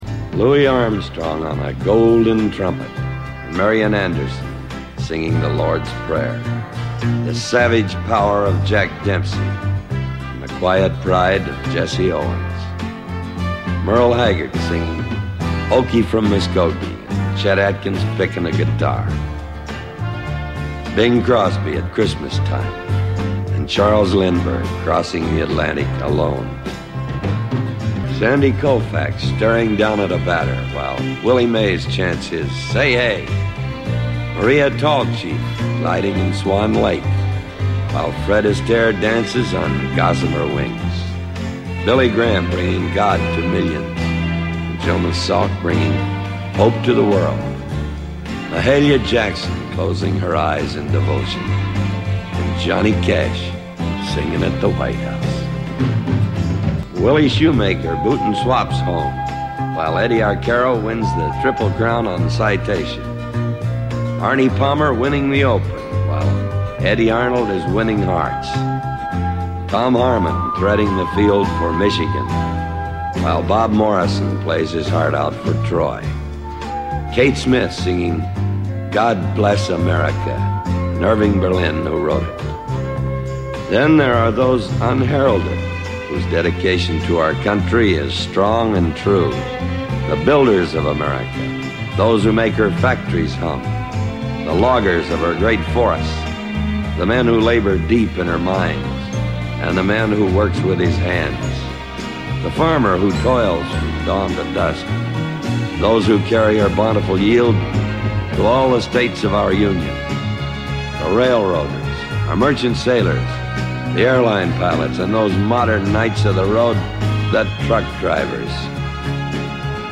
It was an hour of eerie, slack jawed silence as we listened to the rich, languid voice of John Wayne tell us about America.